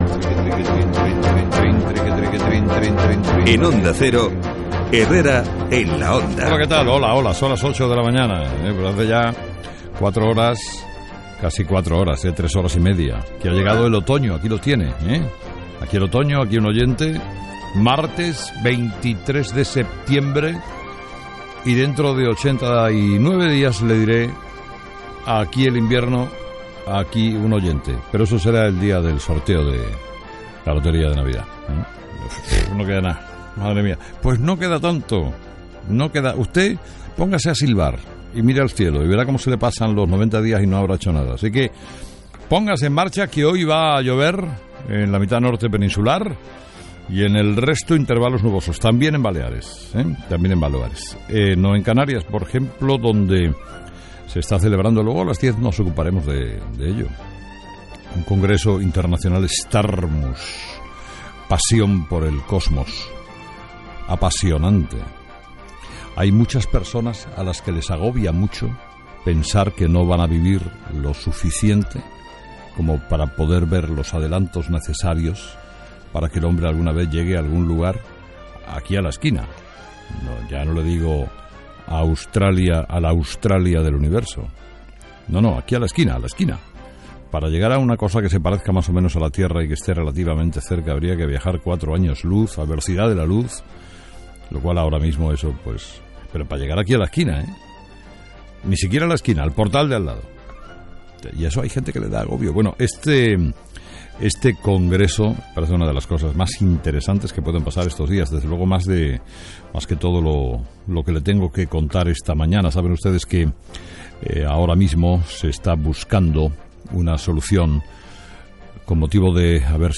23/09/2014 Editorial de Carlos Herrera: "Hay que acabar con estos tíos del Estado Islámico"